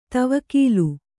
♪ tavakīlu